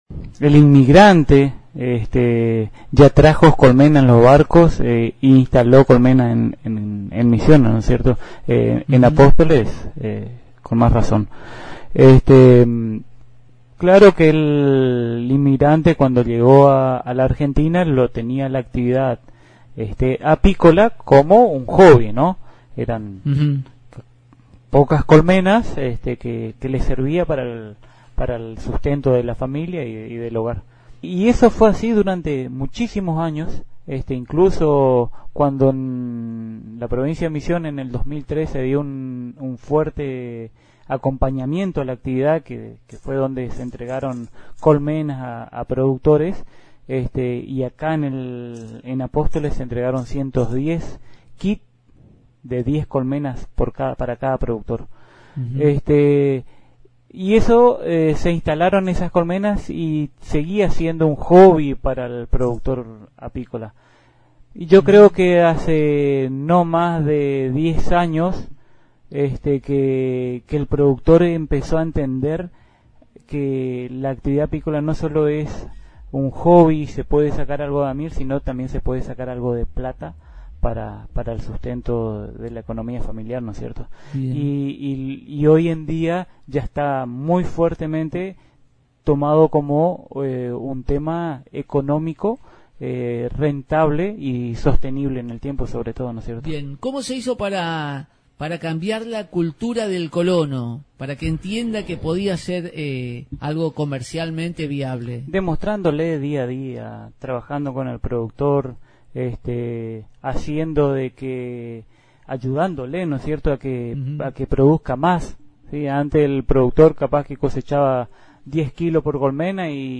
En su última visita a Radio Elemental